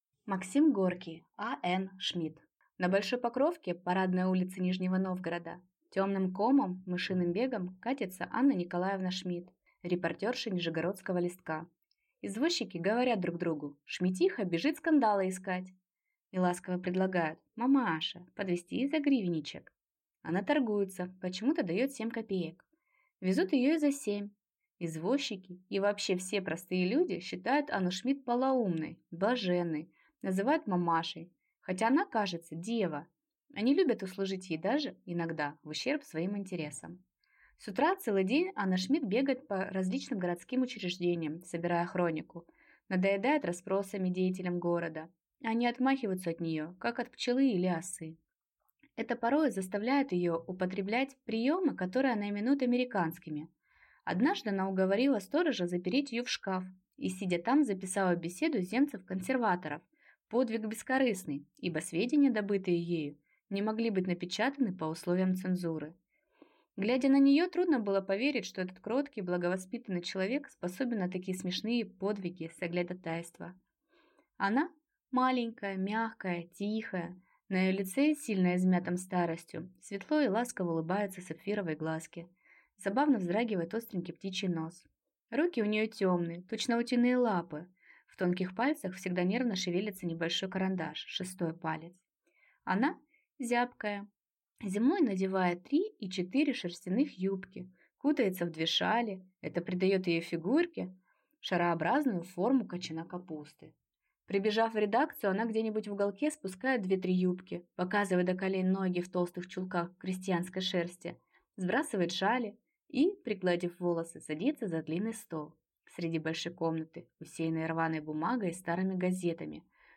Аудиокнига А. Н. Шмит | Библиотека аудиокниг